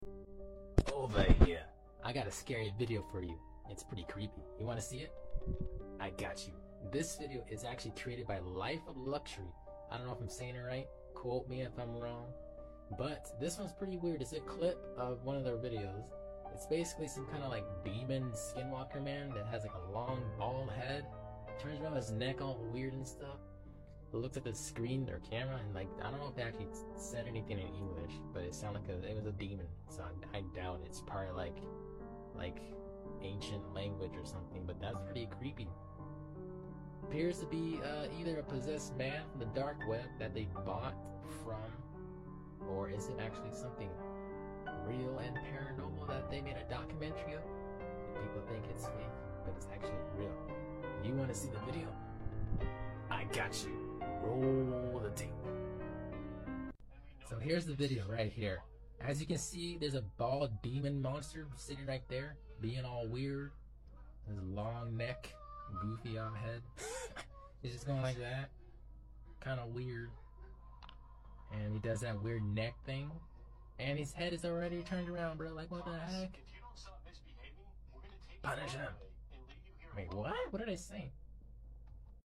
Life Of Luxury Creepy Long Sound Effects Free Download